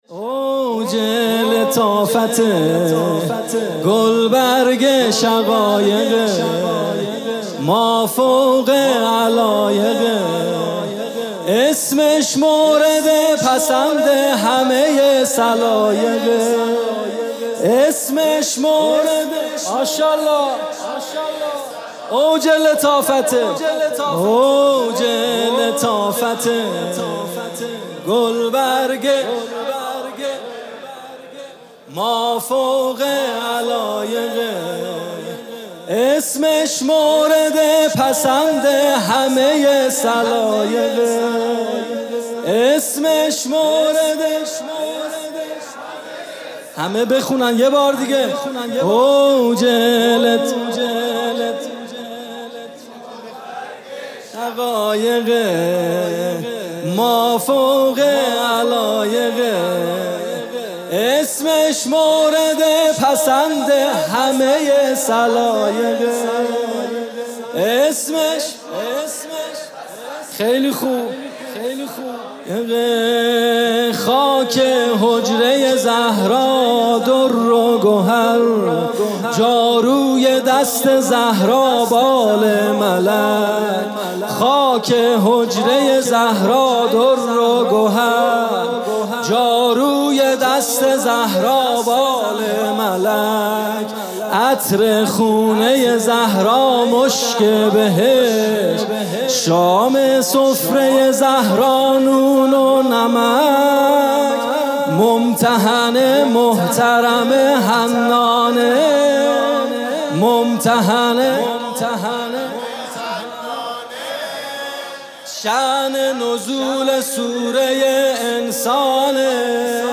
music-icon سرود: خاک حجره زهرا درّ و گهر...